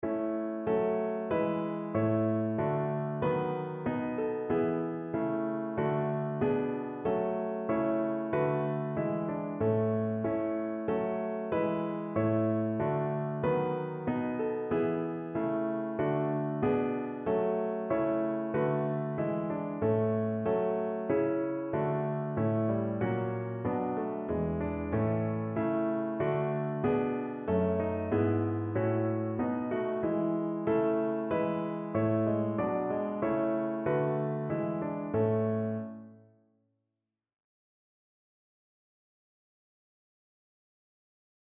Lob und Anbetung
Notensatz 1 (4 Stimmen gemischt)